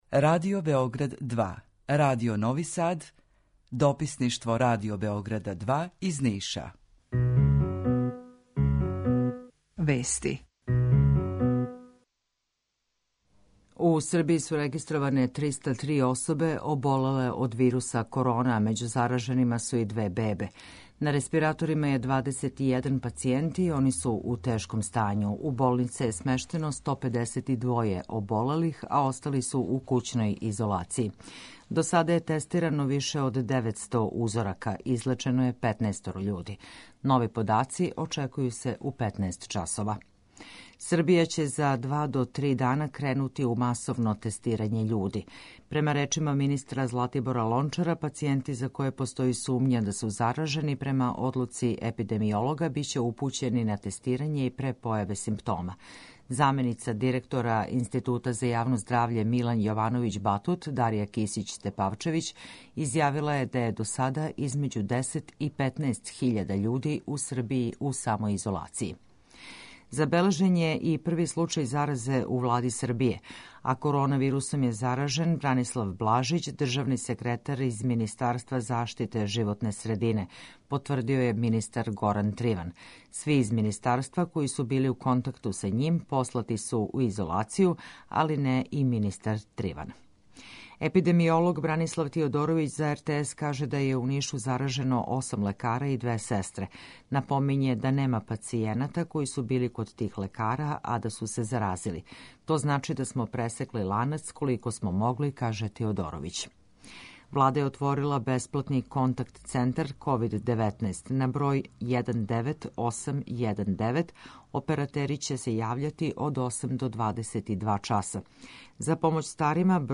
Укључење Радио Грачанице
Јутарњи програм из три студија